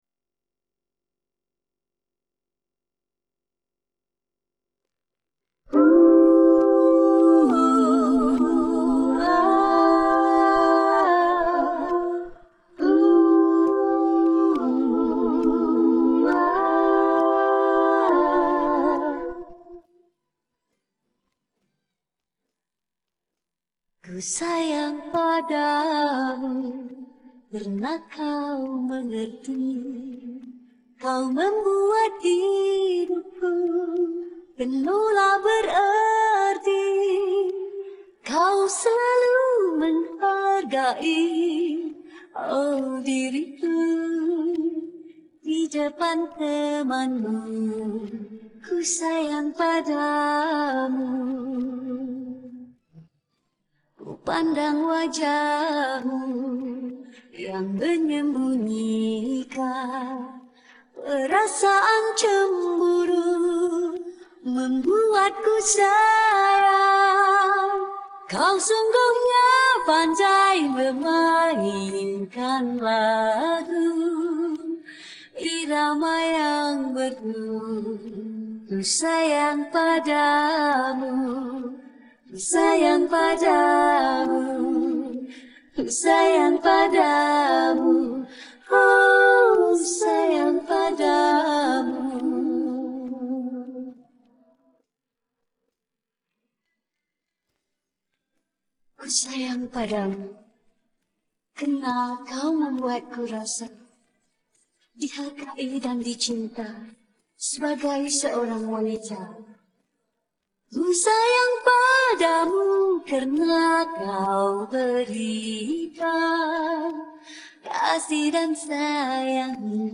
Human voices and background music
Vocal Part